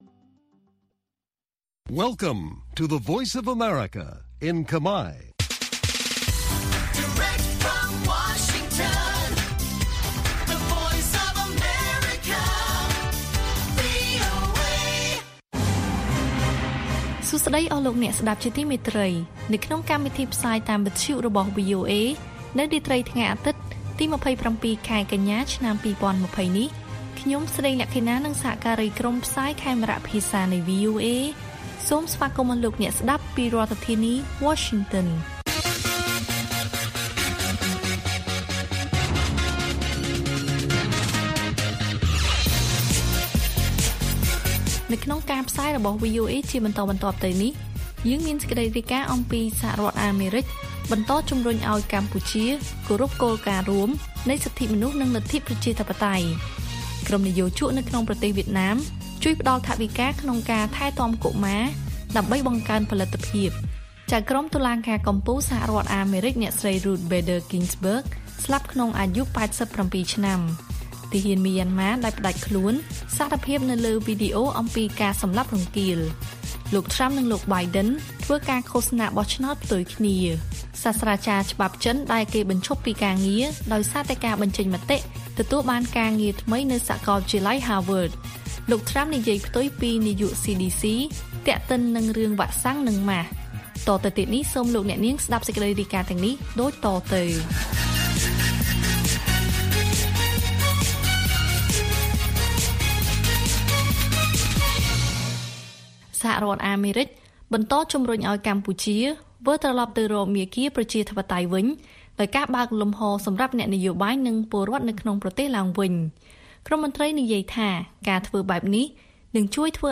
ព័ត៌មានពេលរាត្រី៖ ២៧ កញ្ញា ២០២០